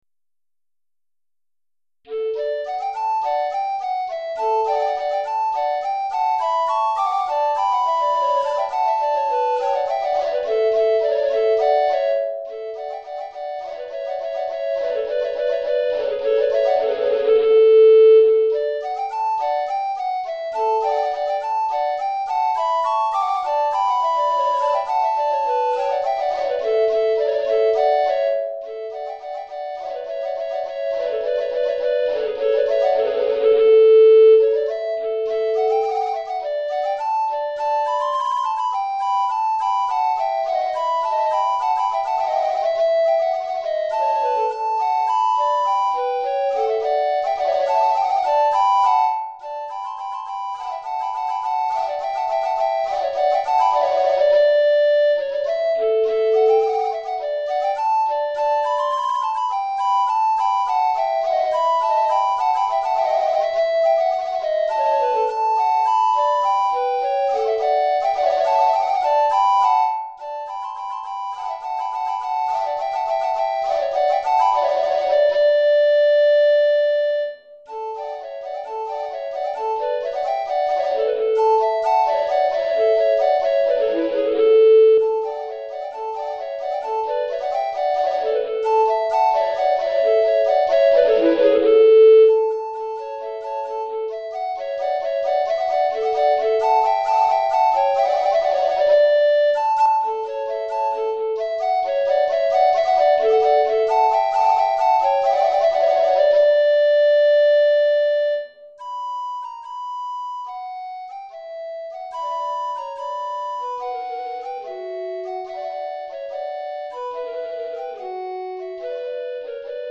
2 Flûtes à Bec Soprano